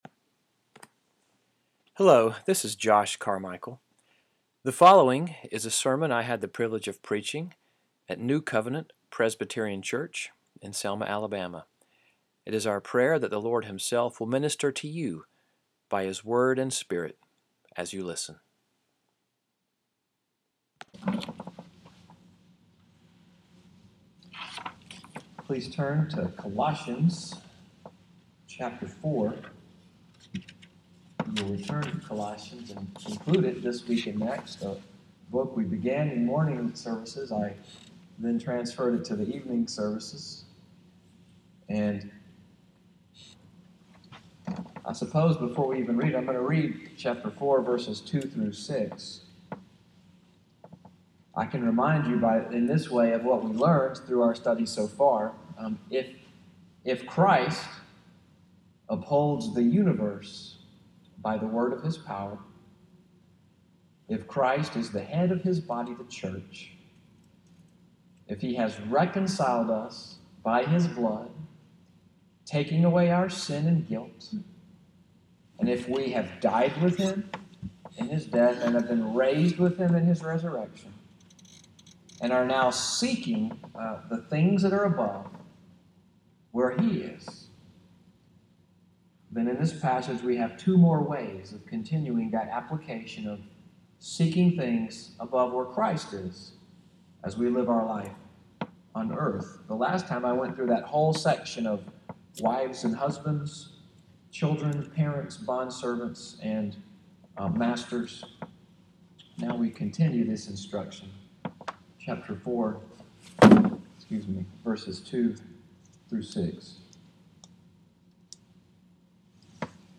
SUNDAY EVENING WORSHIP at NCPC, “Attitude of Ministry,” May 14, 2017